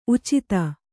♪ ucita